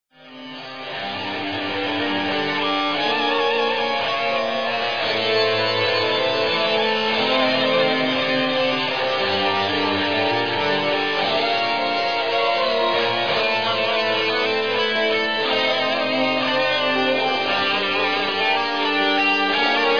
Rock - Speed/Thrash/Death Metal